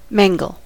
mangle: Wikimedia Commons US English Pronunciations
En-us-mangle.WAV